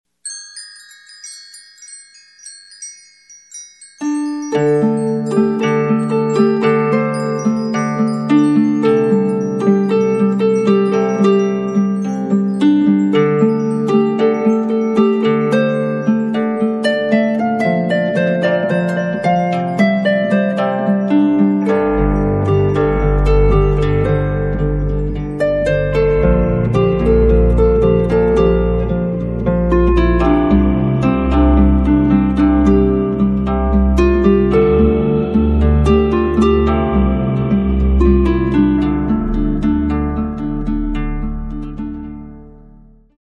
Musique pour pièces de théâtre